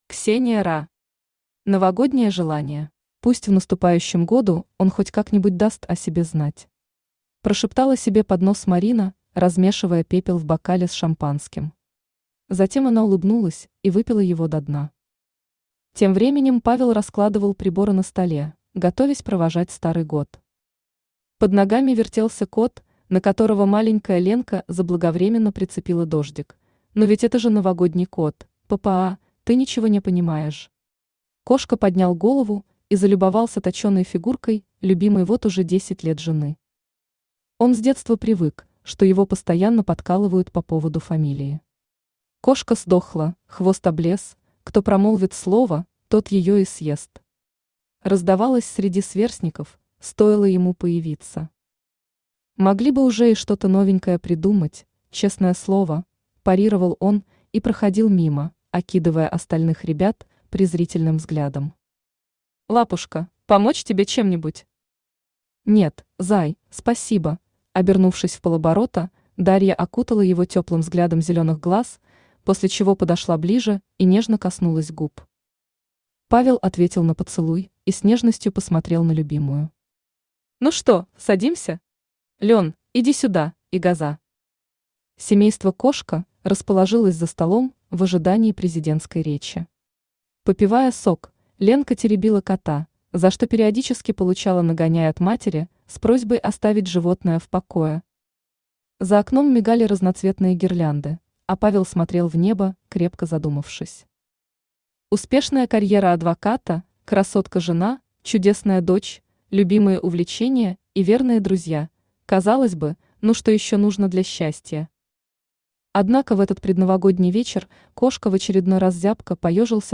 Аудиокнига Новогоднее желание | Библиотека аудиокниг